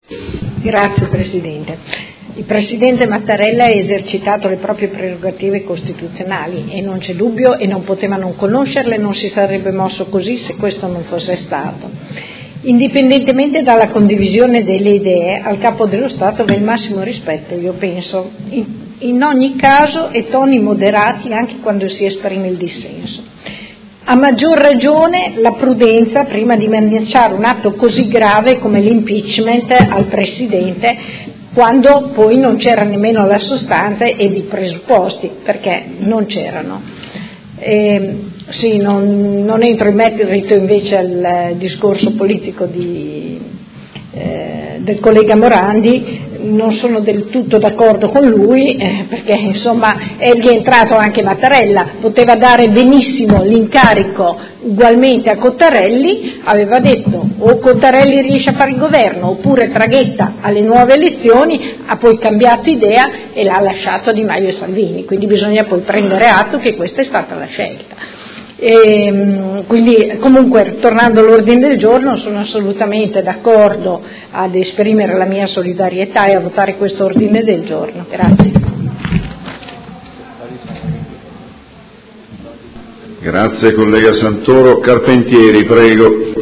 Seduta del 04/06/2018. Dibattito su Ordine del Giorno presentato dai Consiglieri Poggi, Bortolamasi, De Lillo, Venturelli, Arletti Baracchi, Forghieri, Pacchioni, Liotti, Carpentieri e Lenzini (PD) e dal Consigliere Trande (Gruppo Articolo 1-MDP – PerMeModena) avente per oggetto: Solidarietà e sostegno al Presidente della Repubblica